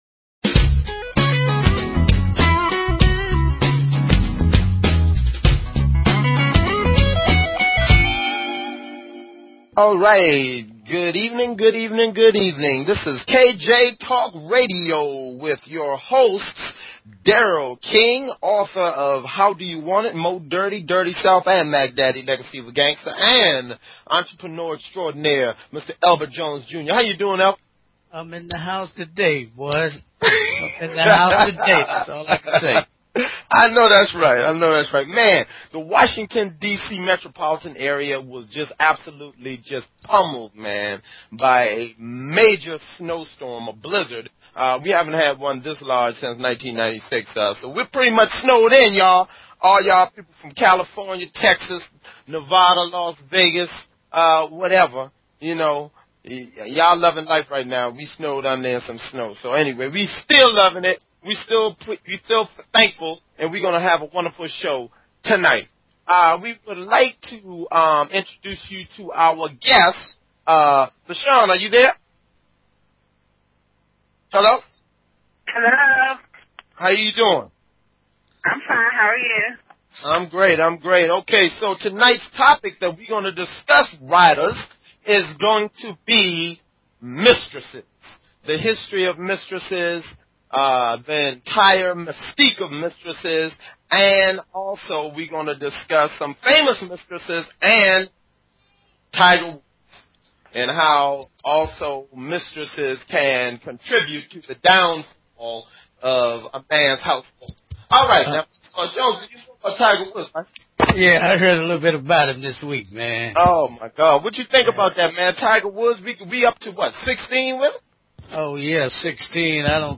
Talk Show Episode, Audio Podcast, KJ_Talk_Radio and Courtesy of BBS Radio on , show guests , about , categorized as
KJ Talk radio is an un opinionated, and open forum which provides a platform for a wide variety of guests, and callers alike.